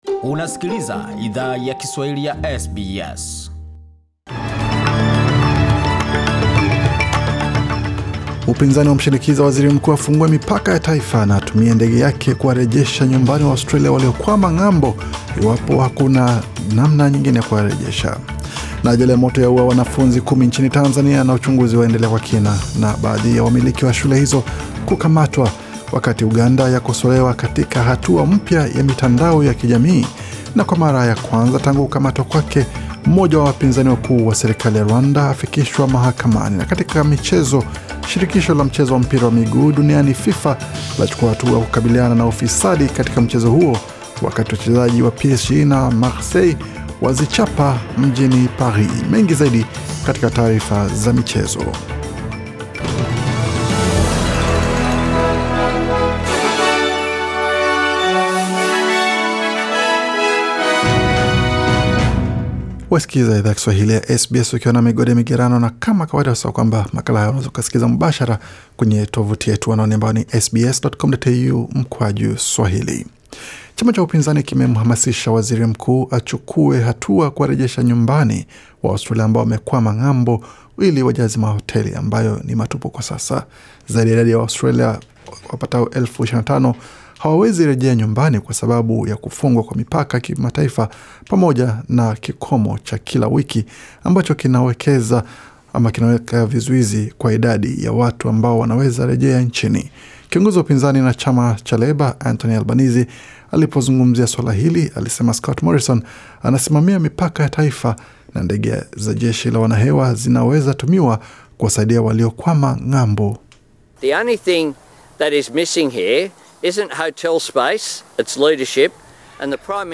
Taarifa ya habari 15 Septemba 2020